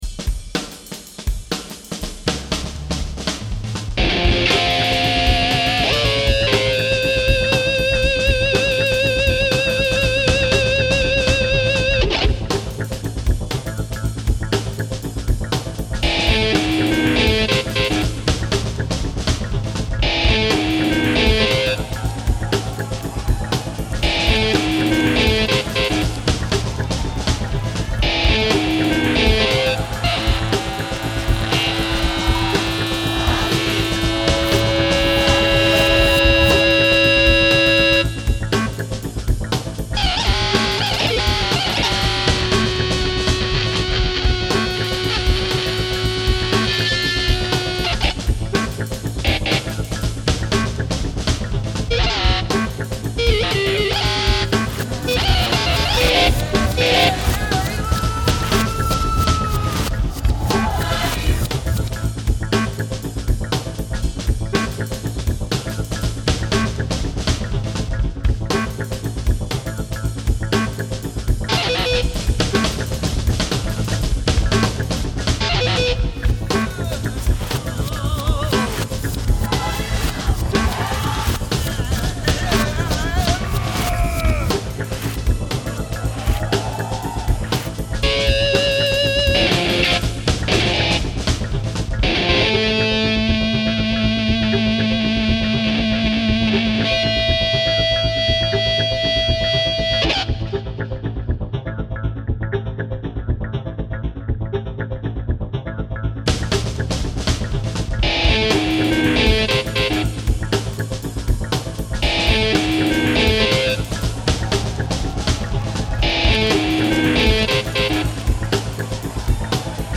Creator's Tags: Audio Collage